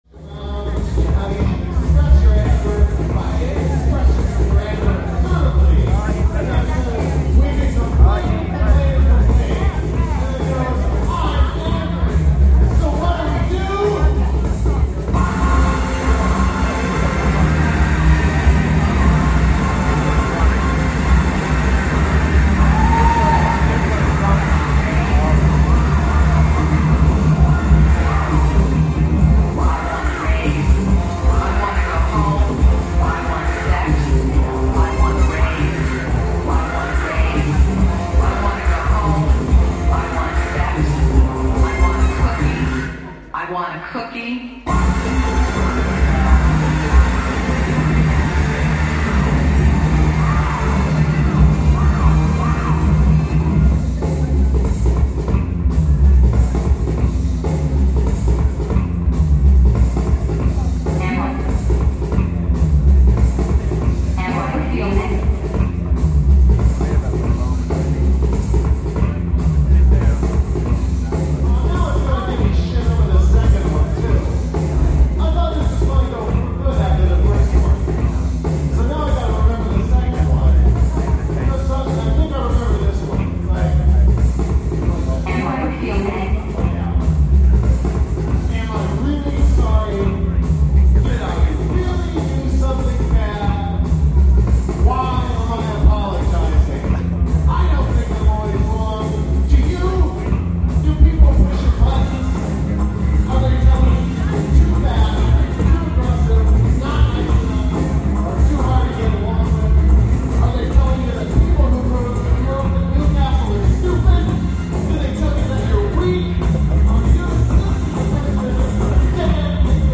live excerpt (malfunctioning Thimbletron)